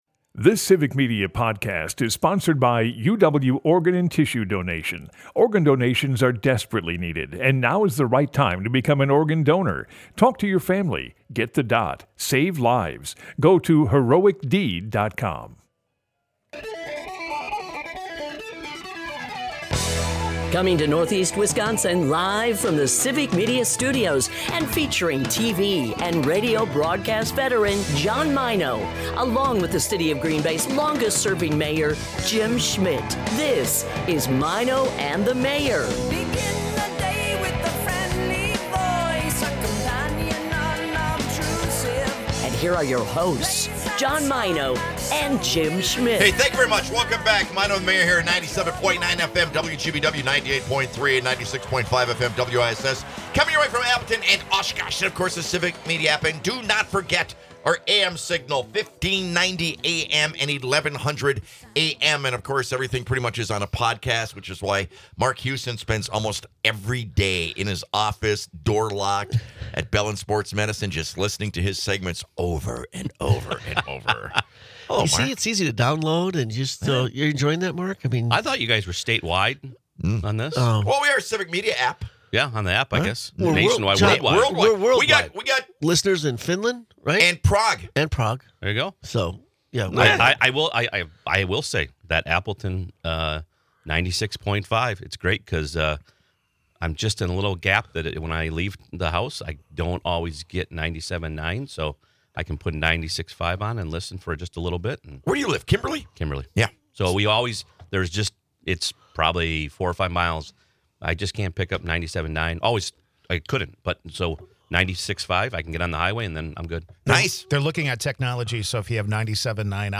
Broadcasts live 6 - 9am in Oshkosh, Appleton, Green Bay and surrounding areas.